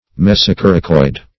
Search Result for " mesocoracoid" : The Collaborative International Dictionary of English v.0.48: Mesocoracoid \Mes`o*cor"a*coid\, n. [Meso- + coracoid.]